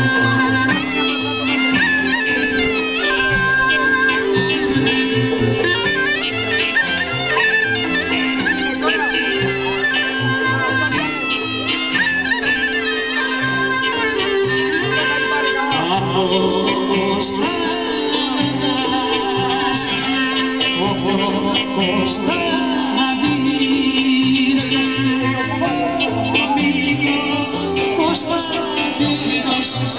Tanz und Zuschauer - 13:56 (20/46) O-Ton: Html5-Audio wird von Ihrem Browser nicht unterstützt; verwenden Sie eine aktuelle Version von Firefox, Opera oder Chrome.
Download audio file / Tondatei herunterladen: Musik bei griechischem Volksfest in Albanien / music at greek folk festival in Albania (Die Tondatei können Sie mit dem VLC-Player abspielen.